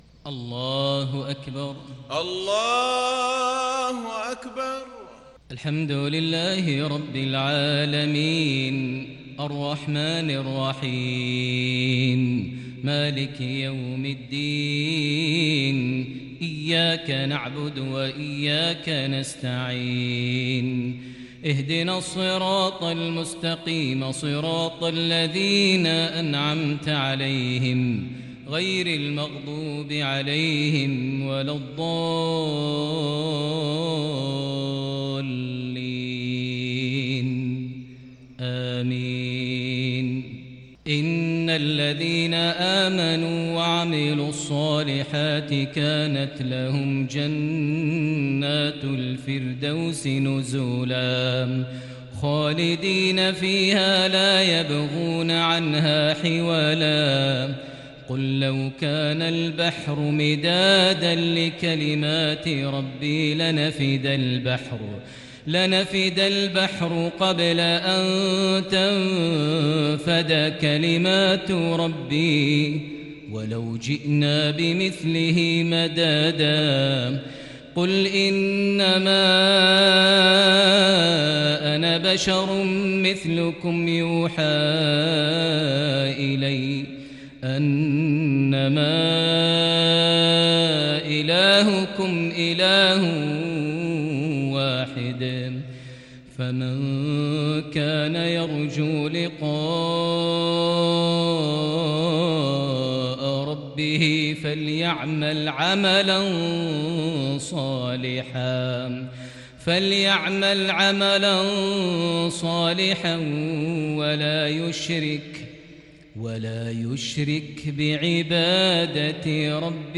صلاة المغرب للشيخ ماهر المعيقلي 22 صفر 1442 هـ
تِلَاوَات الْحَرَمَيْن .